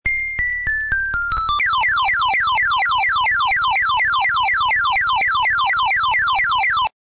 spin_start.mp3